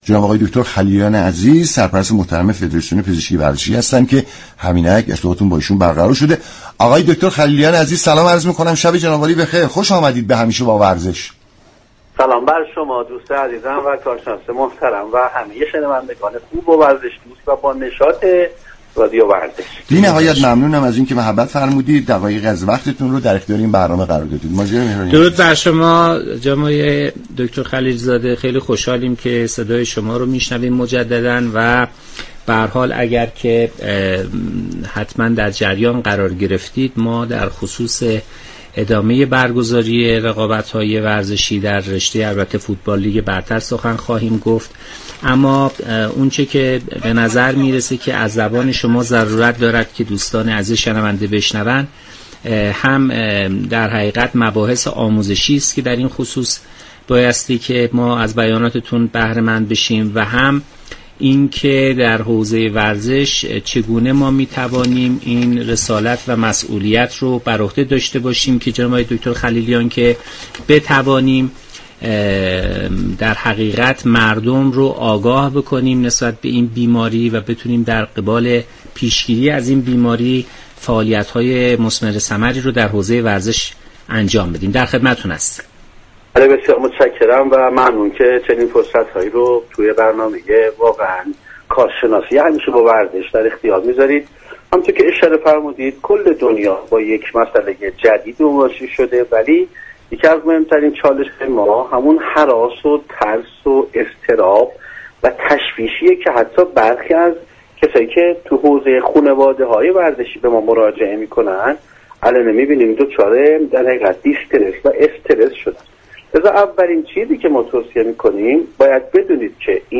شما می توانید از طریق فایل صوتی پیوست شنونده گفتگوی دكتر مهرزاد خلیلیان، سرپرست فدراسیون پزشكی ورزشی در این رابطه باشید.